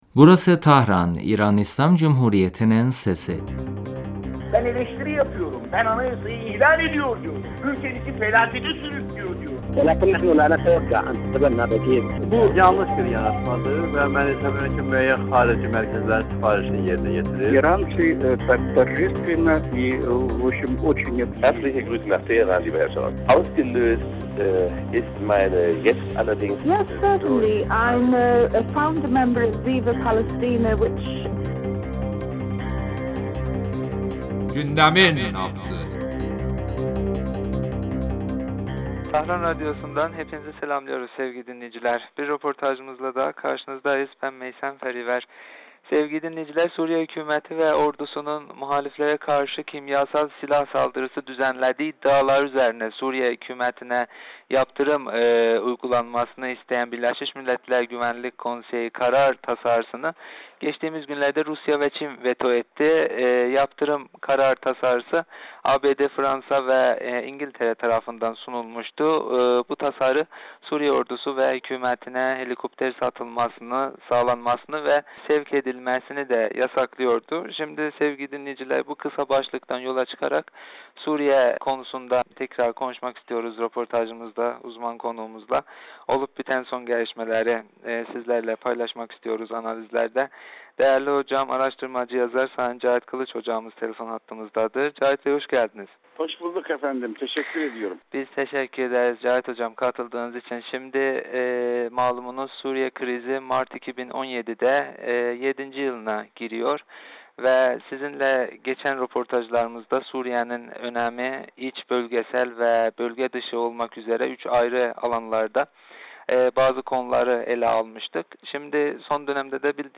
telefon görüşmesinde